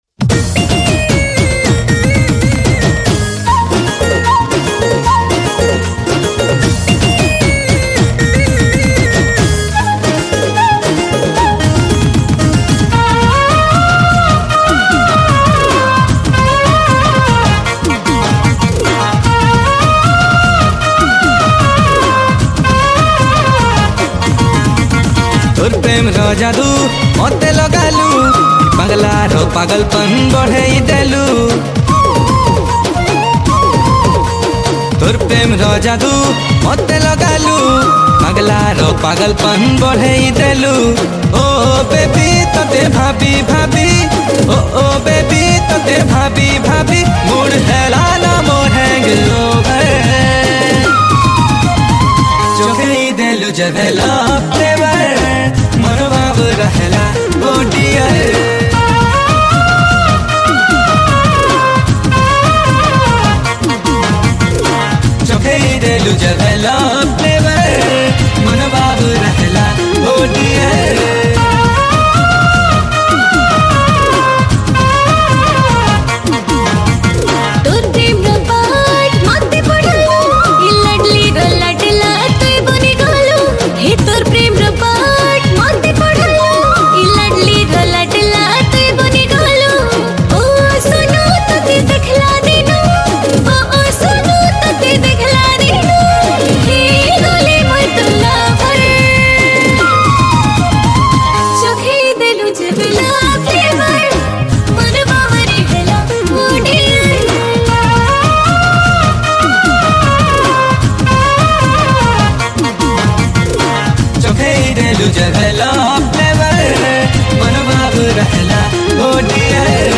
Sambalpuri Song
Category: New Sambalpuri Folk Song 2021